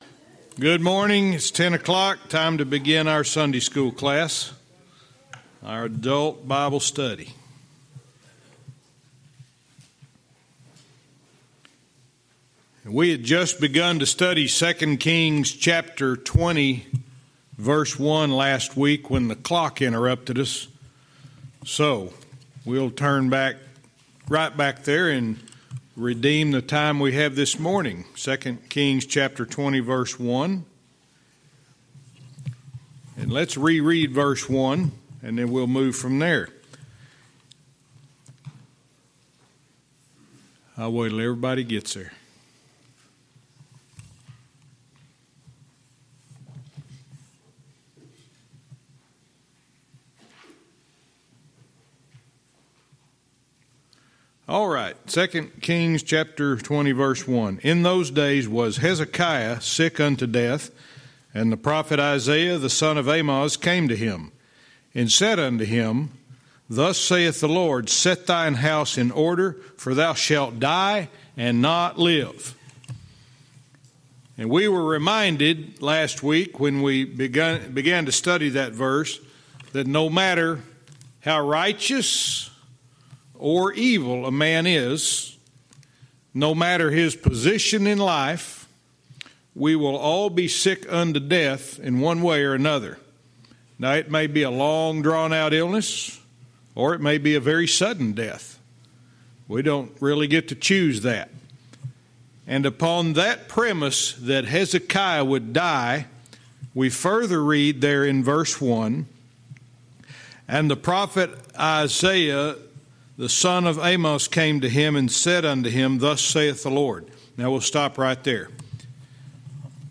Verse by verse teaching - 2 Kings 20:1-3